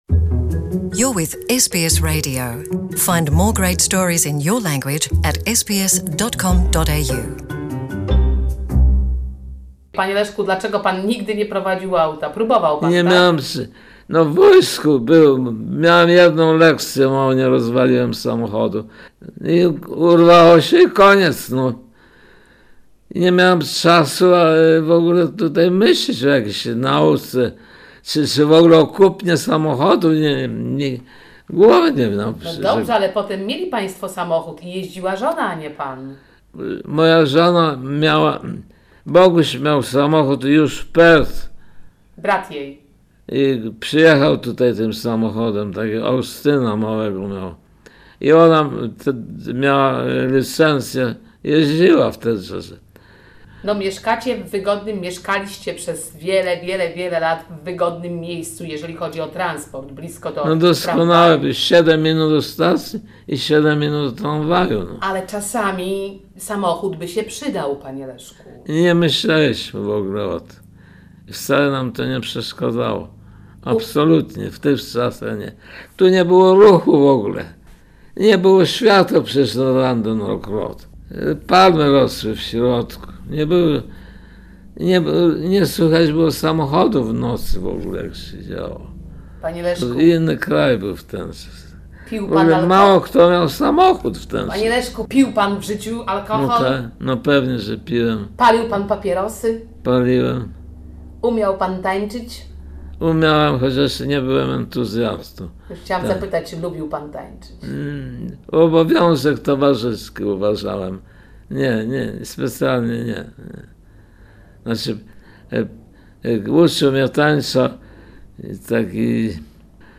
archiwalna rozmowa